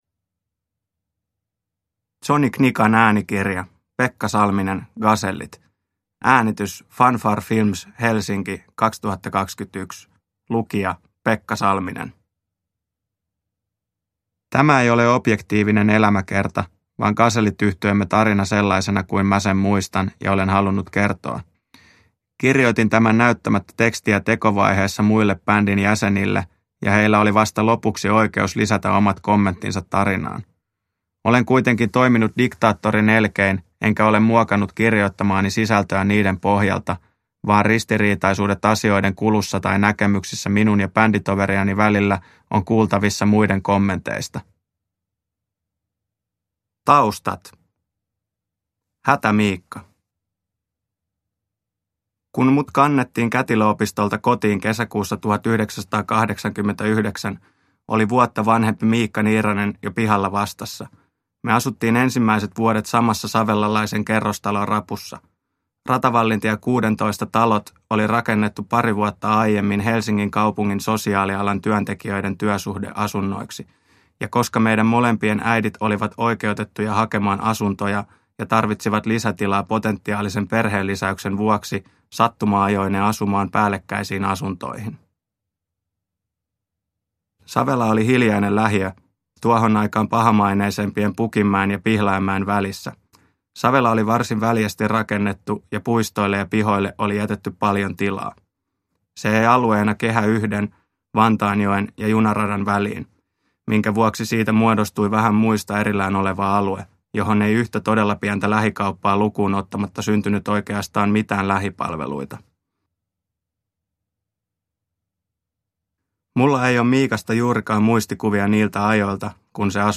Gasellit – Ljudbok – Laddas ner